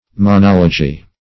Search Result for " monology" : The Collaborative International Dictionary of English v.0.48: Monology \Mo*nol"o*gy\, n. [Gr.
monology.mp3